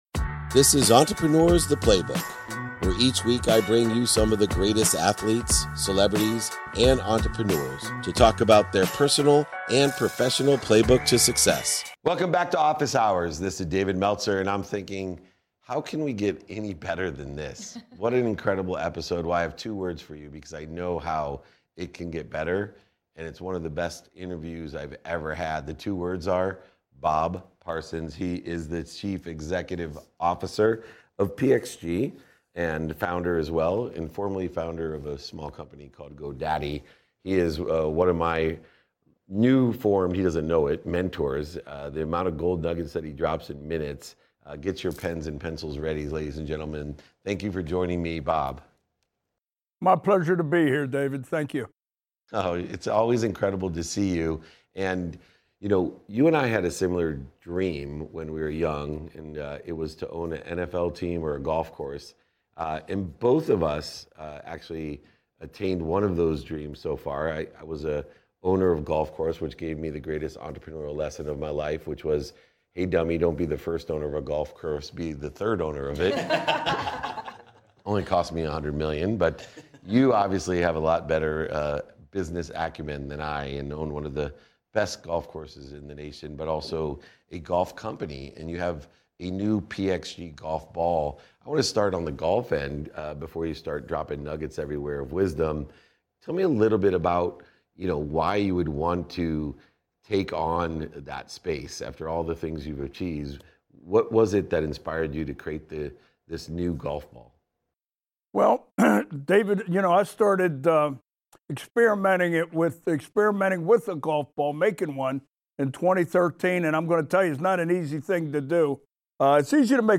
Joined by a panel of co-hosts, we delve into Bob's entrepreneurial journey of disrupting industries, first with Godaddy and now with PXG. Bob emphasizes the significance of following your heart in business and the entrepreneurial lessons learned from his love of golf.